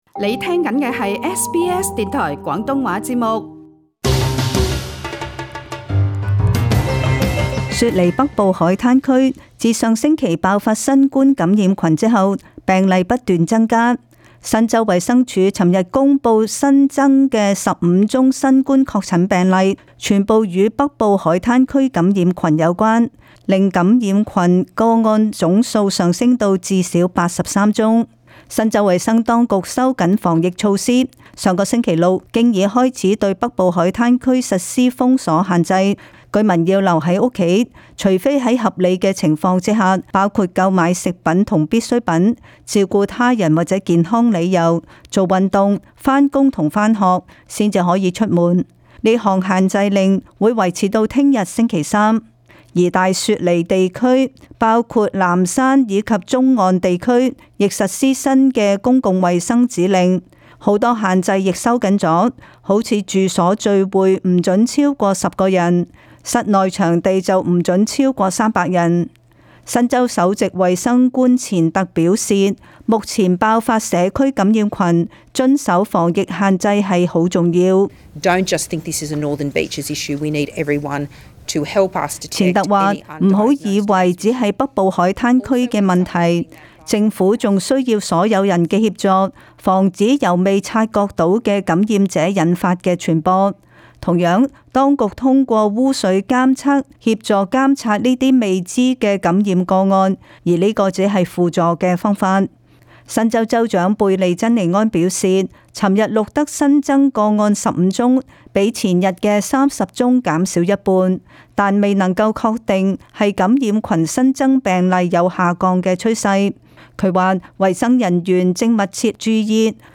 時事報導